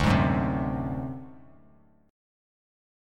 D7b5 chord